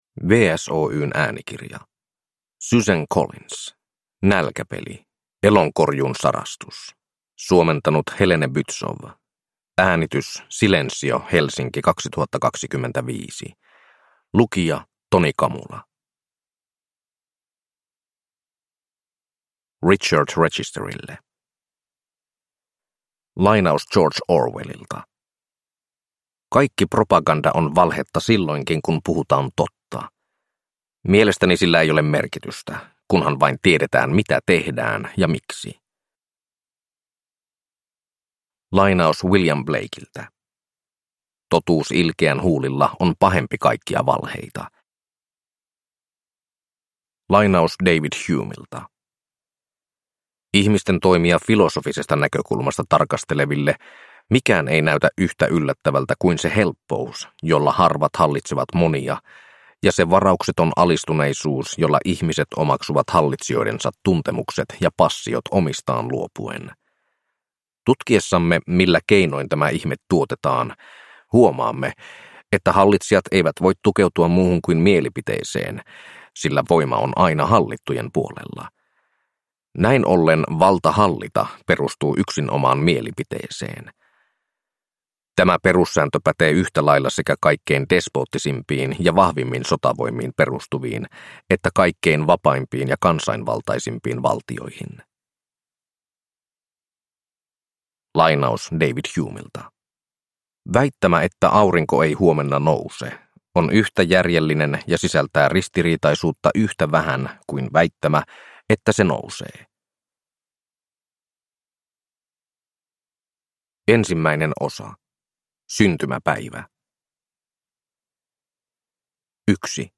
Nälkäpeli: Elonkorjuun sarastus (ljudbok) av Suzanne Collins